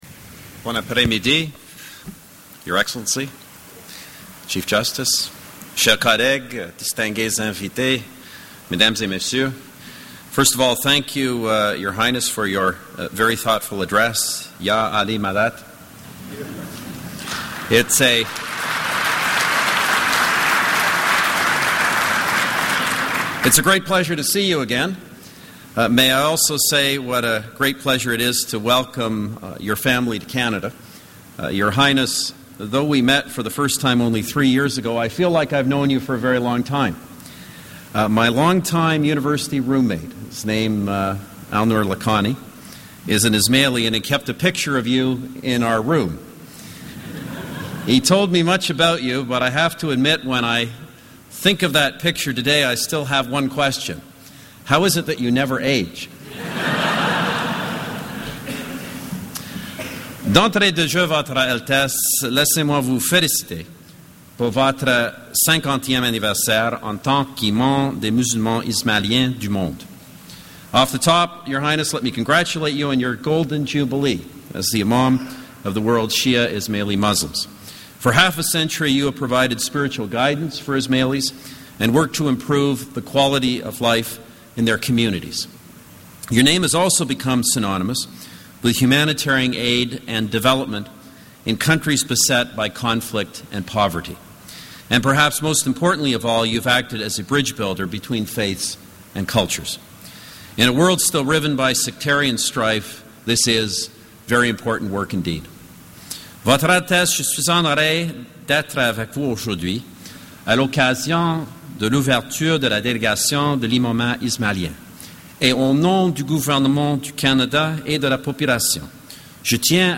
Audio: Prime Minister of Canada joins the Aga Khan in opening new Delegation of the Ismaili Imamat